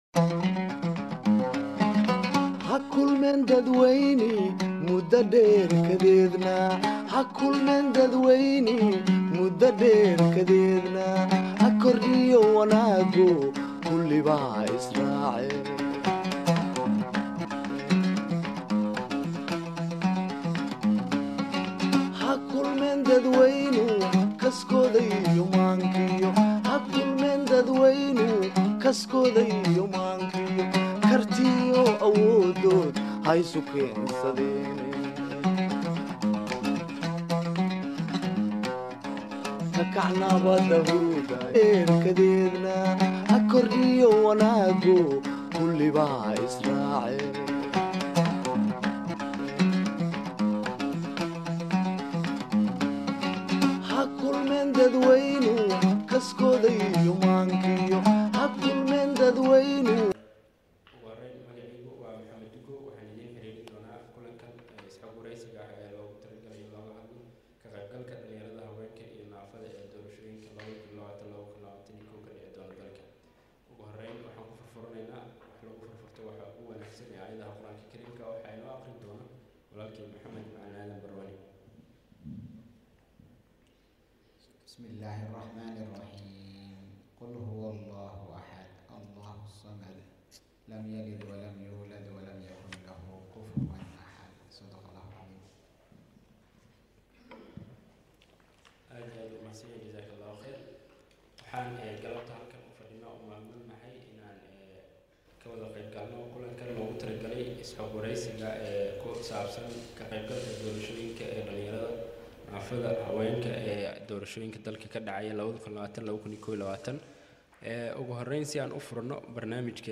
Dood ku saabsan ka qeybgalka Haweenka, Dhaliinyarada iyo naafada ee Doorashooyinka dalka.